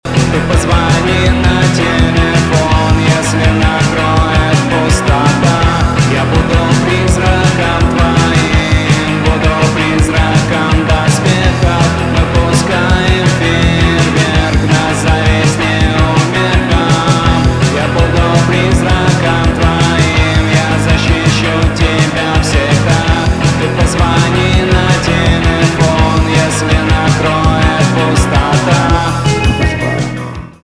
инди
гитара , барабаны
романтические , депрессивные